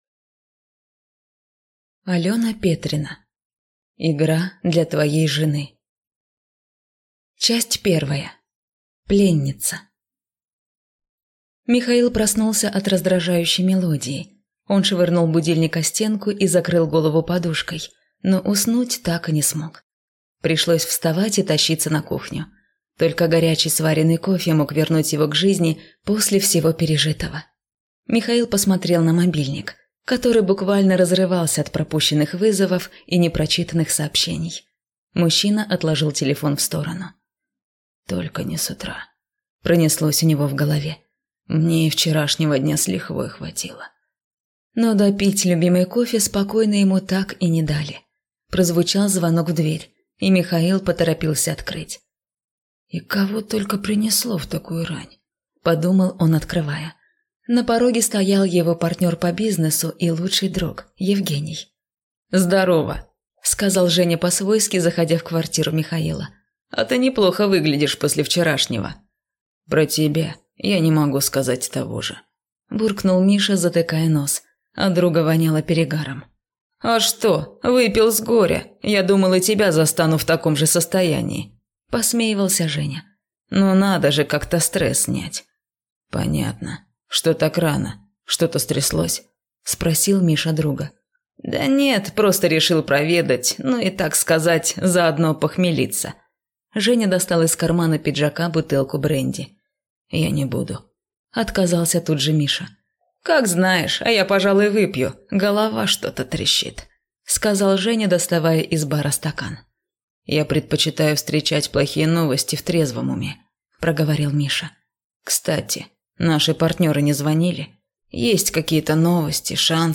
Аудиокнига Игра для твоей жены | Библиотека аудиокниг